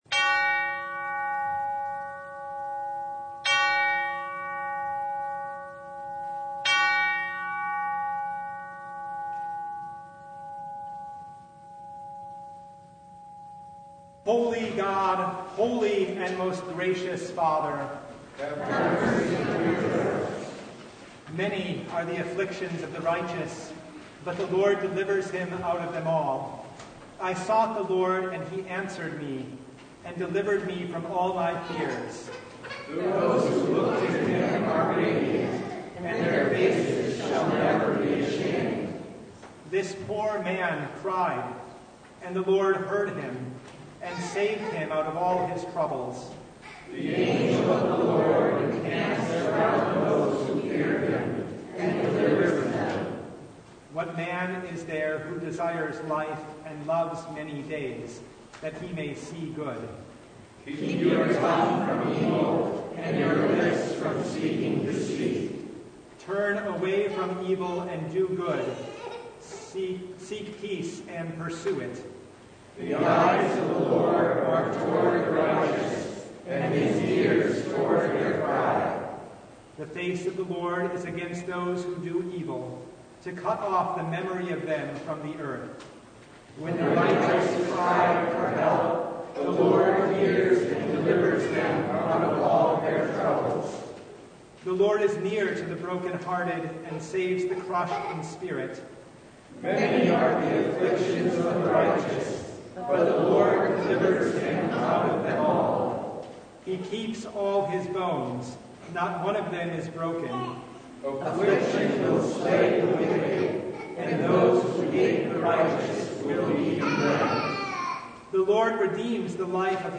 Service Type: Lent Midweek Noon
Topics: Full Service « What’s Love Got to Do with God?